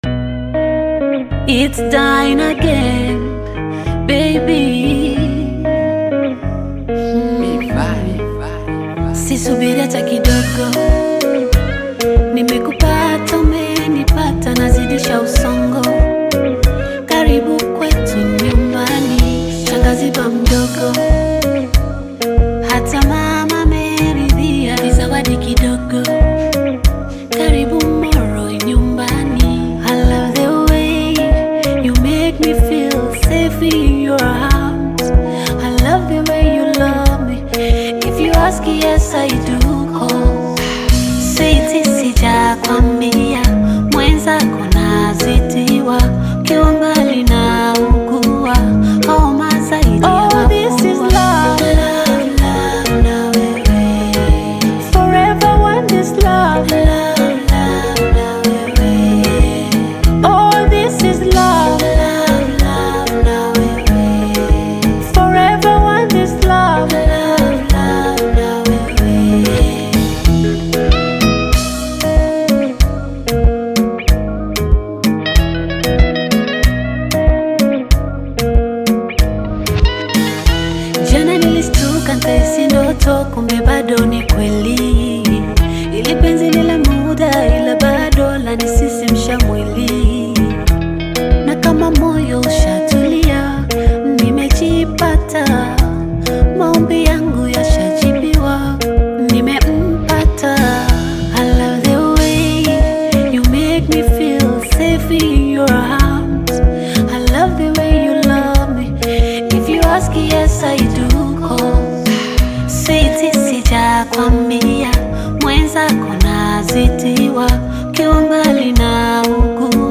smooth, romantic Afro-Beat single
warm melodies
rich rhythmic appeal